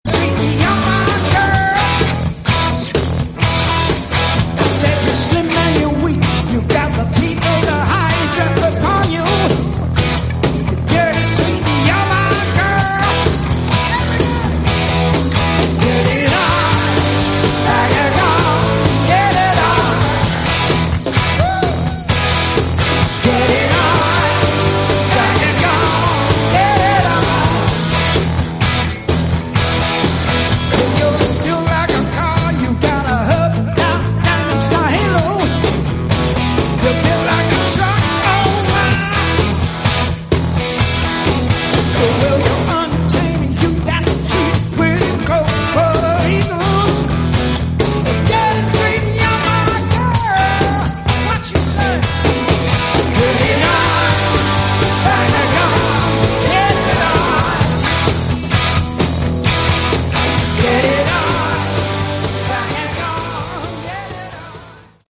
Category: Hard Rock
vocals, guitar
drums
bass
lead guitar